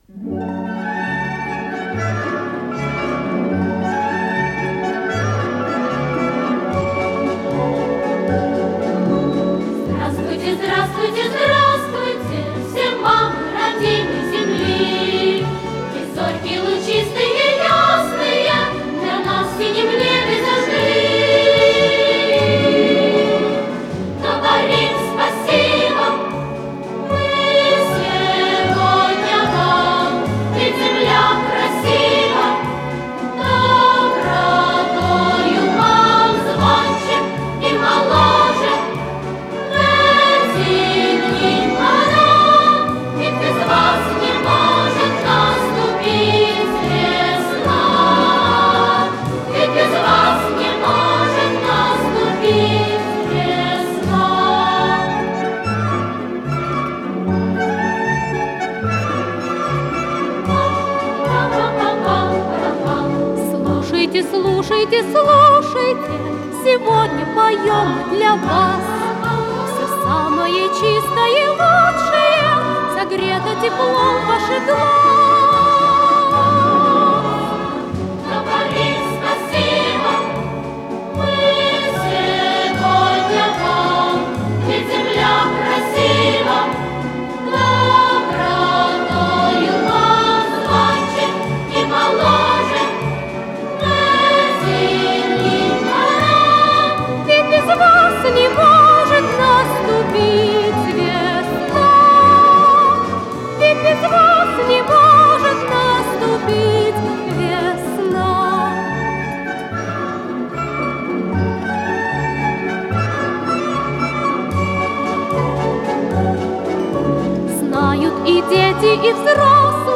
Аккомпанимент
ВариантДубль моно